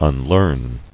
[un·learn]